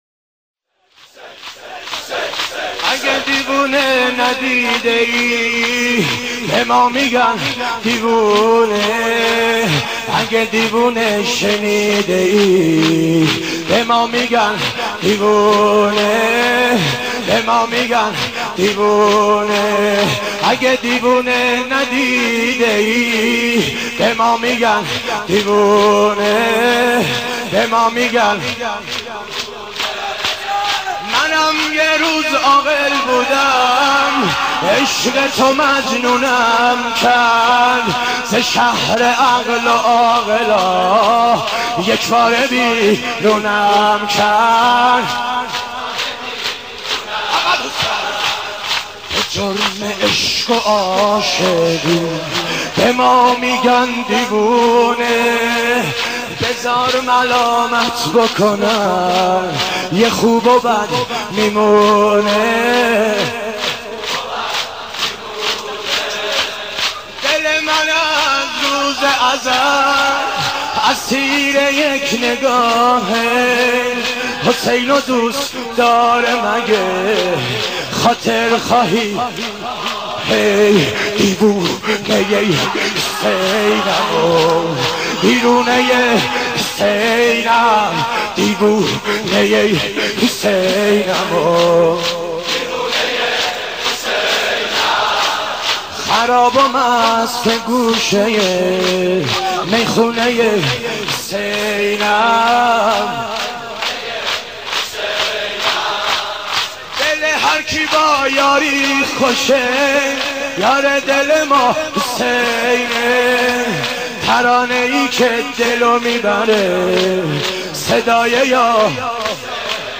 نوحه
مداحی شور